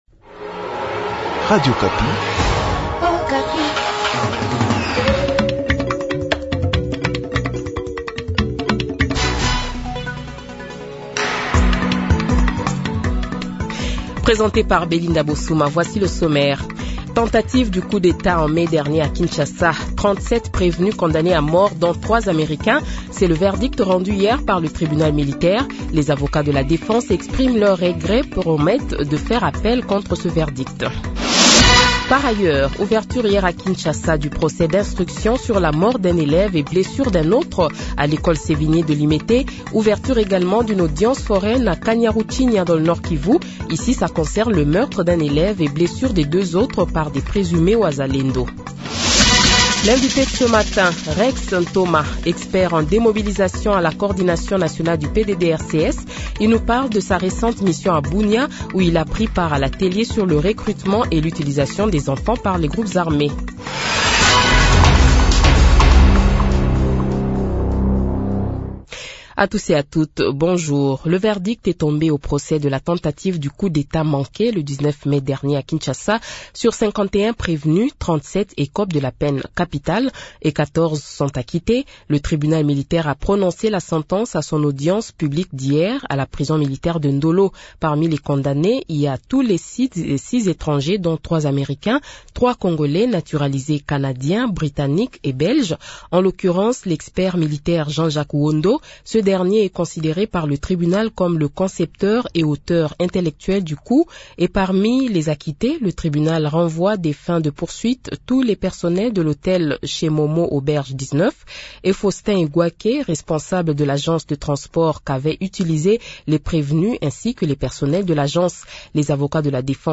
Journal Francais Matin
Le Journal de 7h, 14 Septembre 2024 :